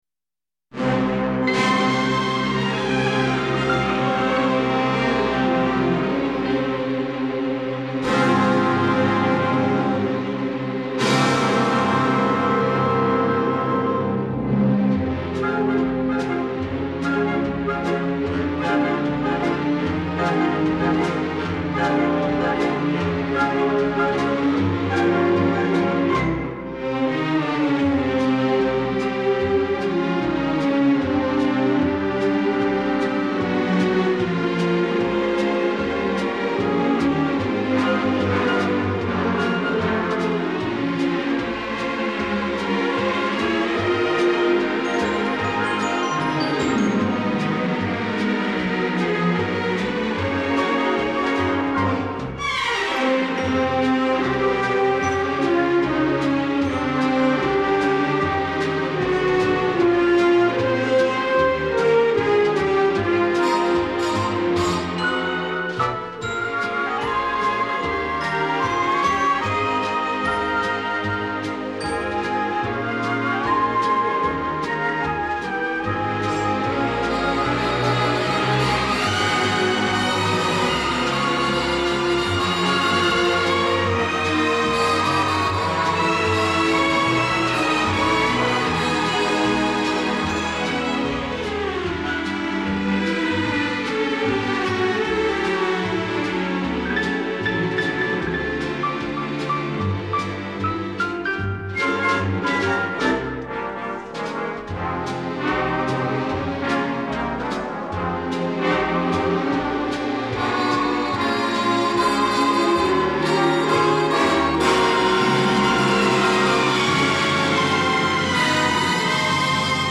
Digital Mastered Stereo